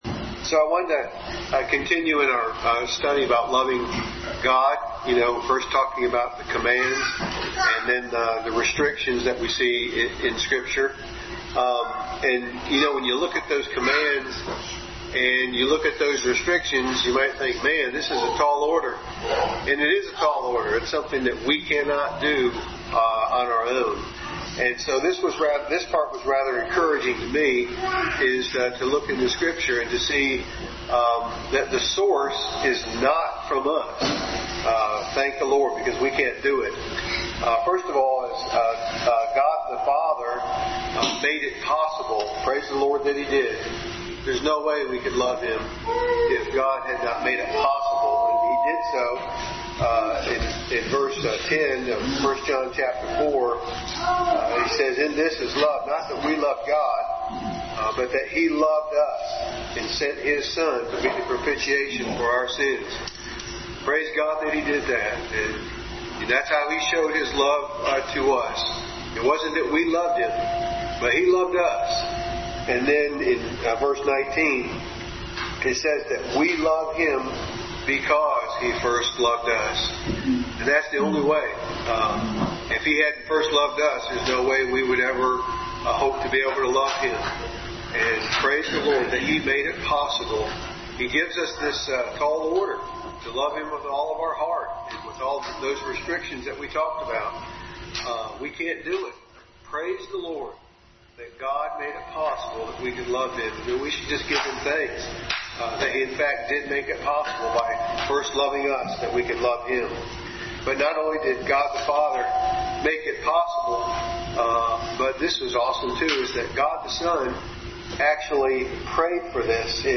Passage: 1 John 4:10, 19, John 17:26, Romans 5:5, 1 Corinthians 16:22, 13:1-3, Luke 11:42, 7:41-47, Matthew 24:12, Revelation 2:4, 1 John 4:19, Service Type: Family Bible Hour